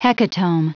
Prononciation du mot hecatomb en anglais (fichier audio)
Prononciation du mot : hecatomb